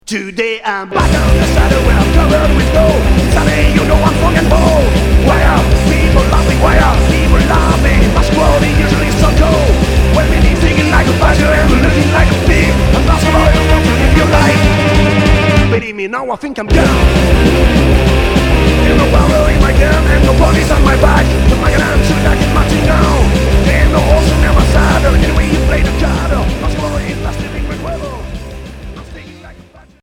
Punk hardcore